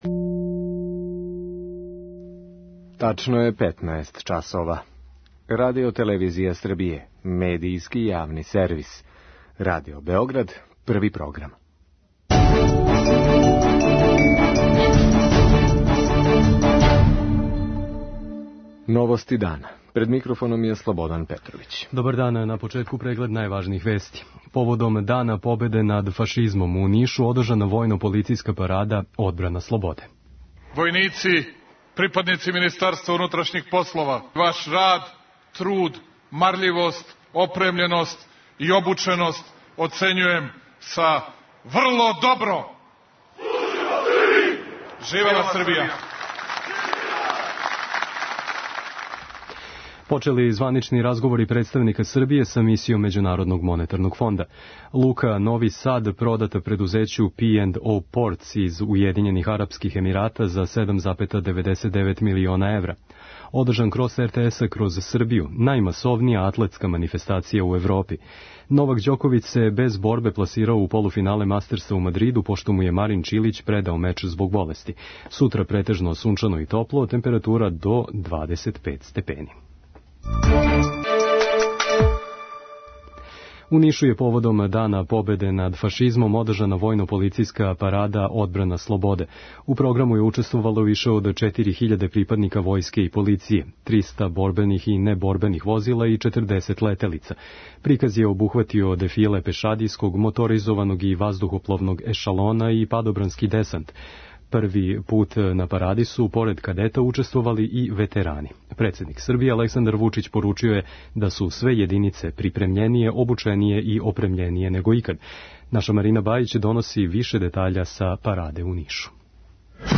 Новости дана